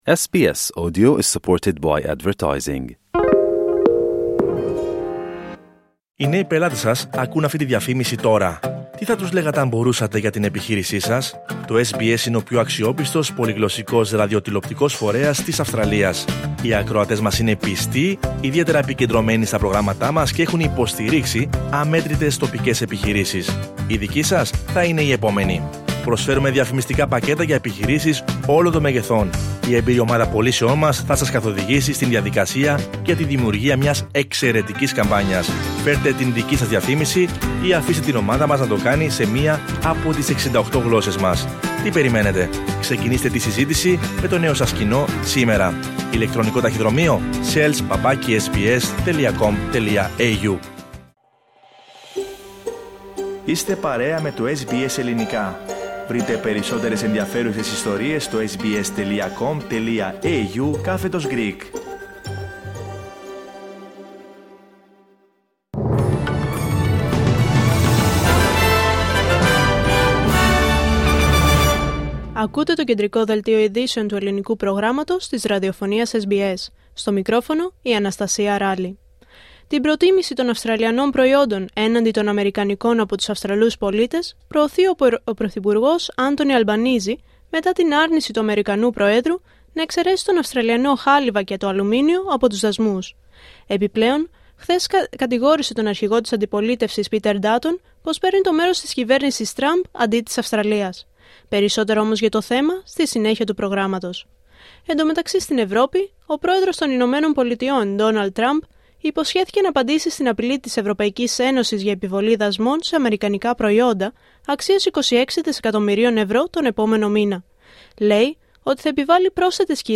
Δελτίο Ειδήσεων Πέμπτη 13 Μαρτίου 2025